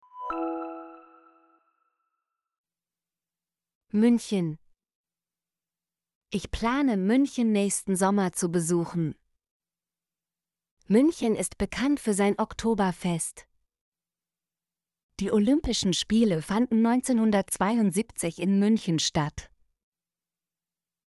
münchen - Example Sentences & Pronunciation, German Frequency List